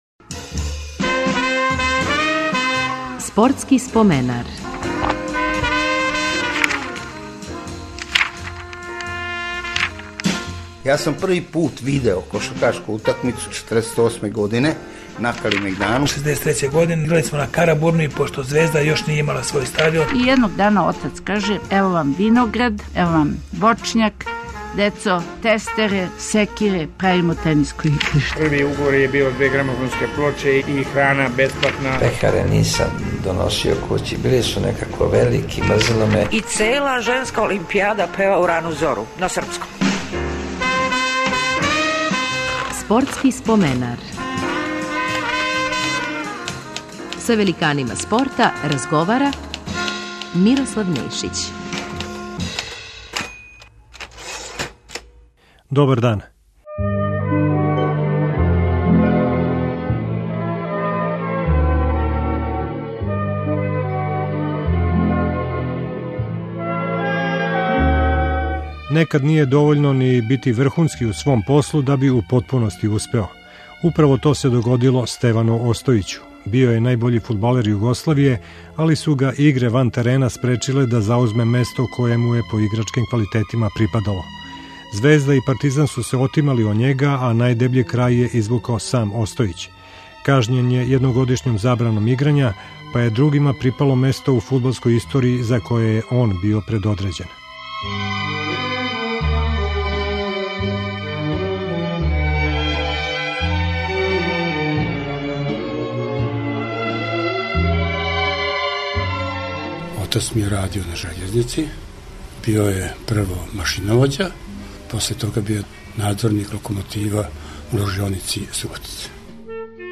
Гост Споменара је фудбалер Стеван Остојић.